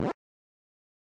WC98_Squish!.oga.mp3